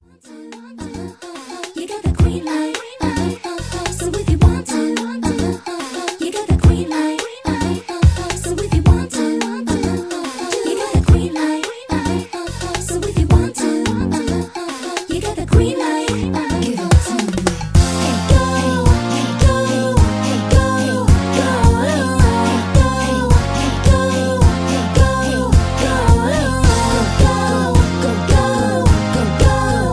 (Key-F) Karaoke MP3 Backing Tracks
Just Plain & Simply "GREAT MUSIC" (No Lyrics).